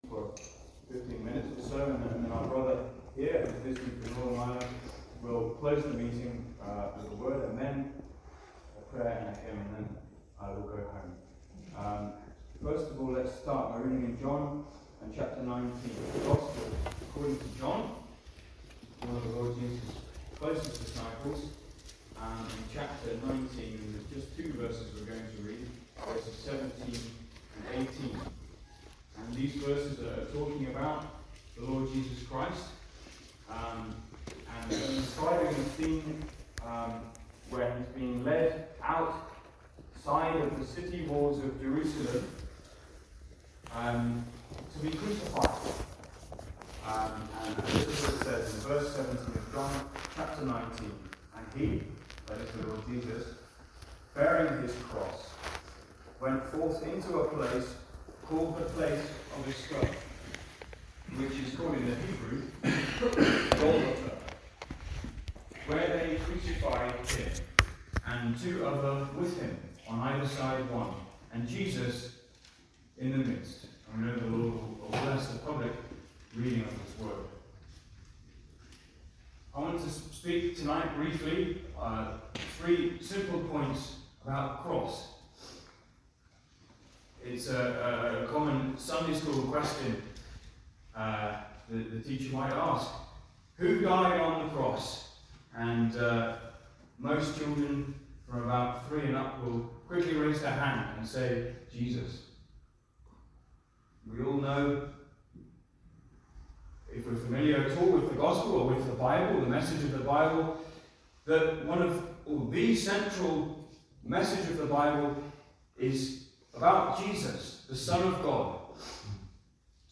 Various Gospel Messages